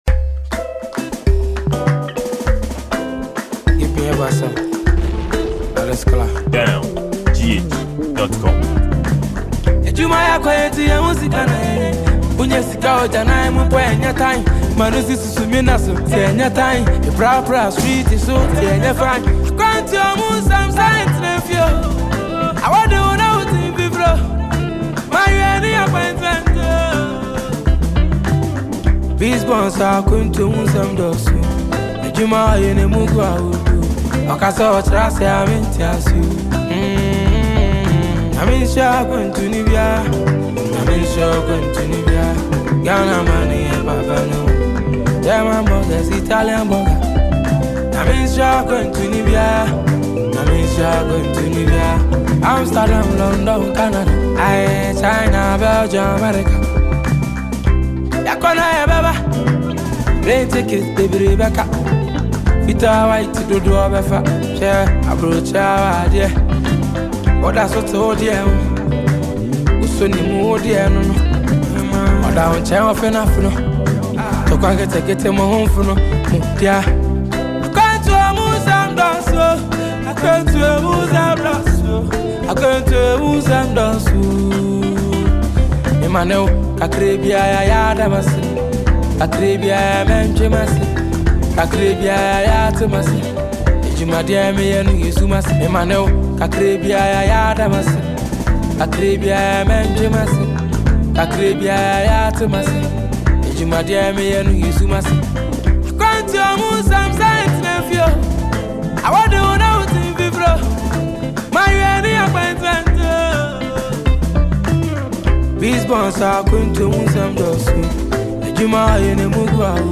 afrobeats blended with Ghanaian highlife genre